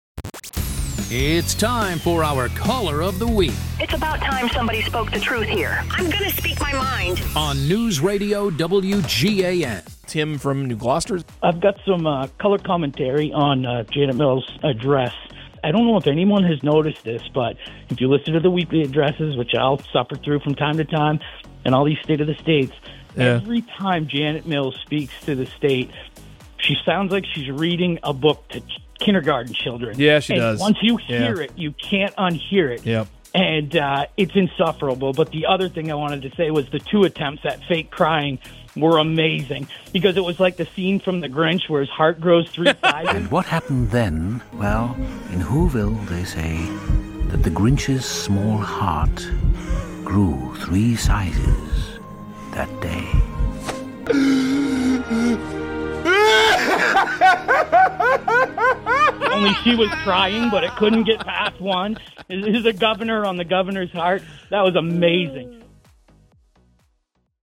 Caller Of The Week 1/30/26